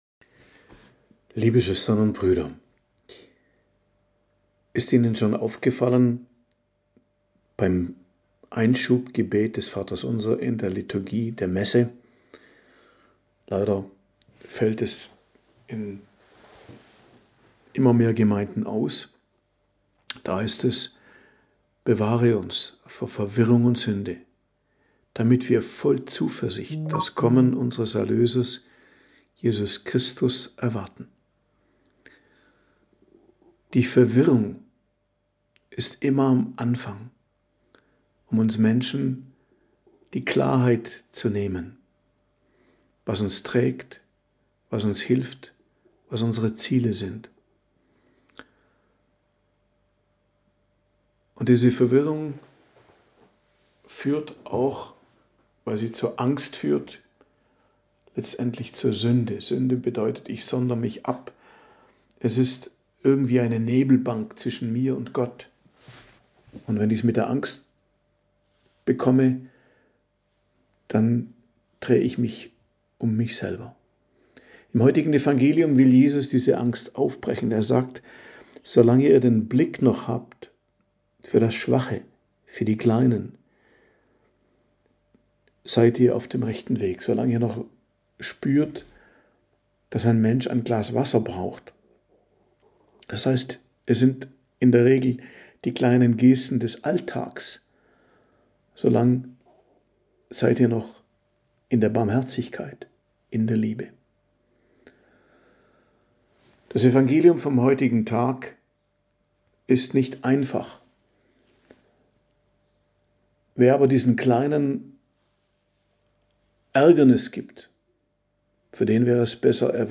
Predigt am Donnerstag der 7. Woche i.J. 27.02.2025